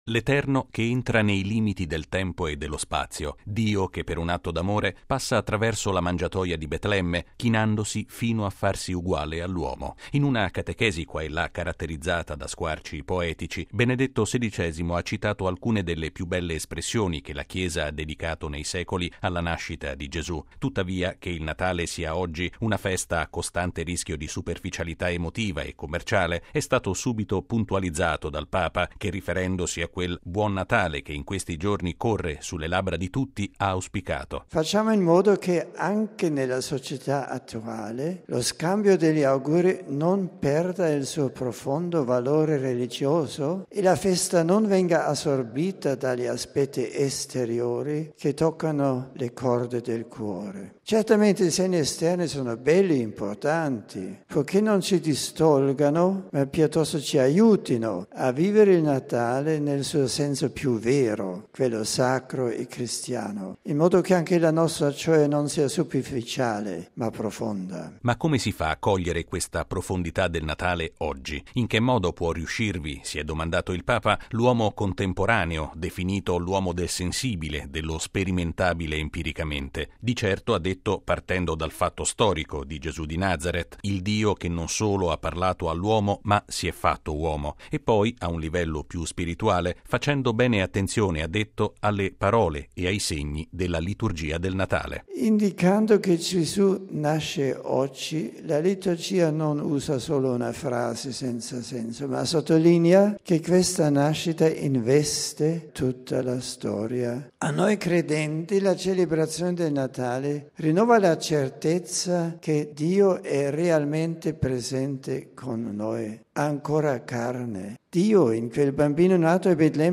È l’auspicio con il quale Benedetto XVI ha aperto la catechesi dell’udienza generale di stamattina in Aula Paolo VI.